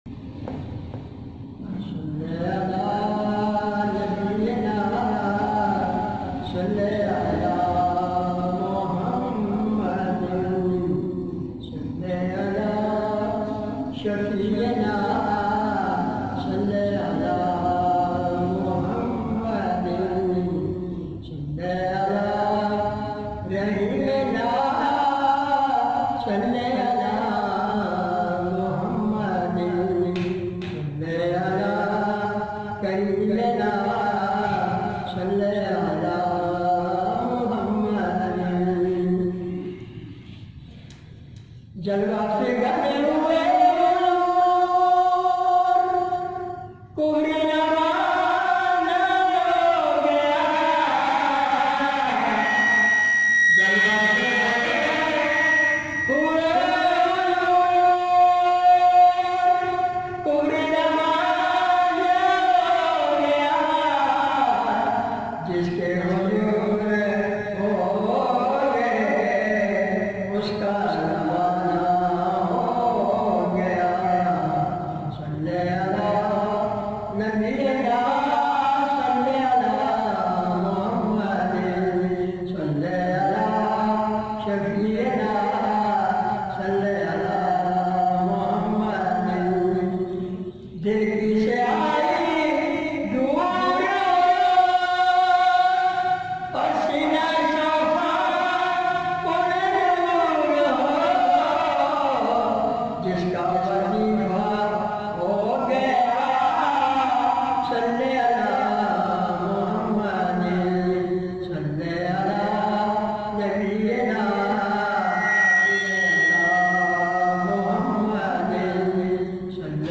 Heart Touching Naat
in best audio quality